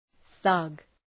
thug.mp3